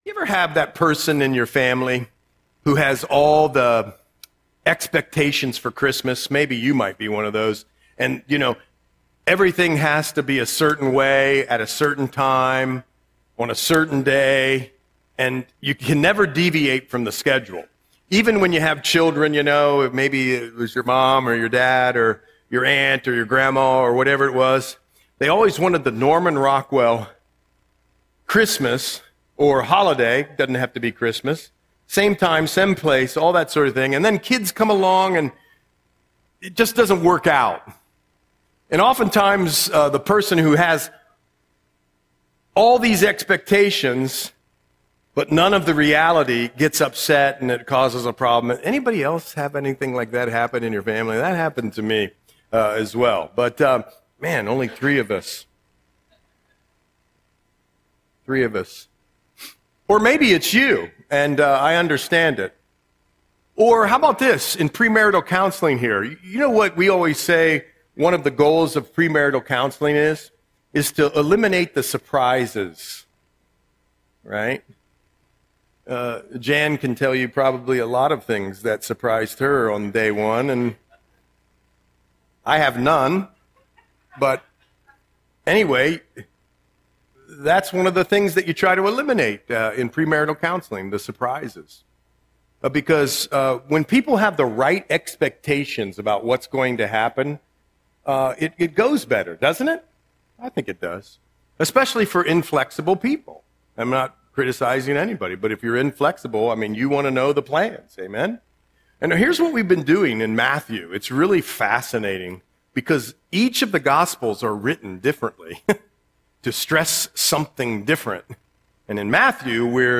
Audio Sermon - February 8, 2026